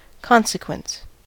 consequence: Wikimedia Commons US English Pronunciations
En-us-consequence.WAV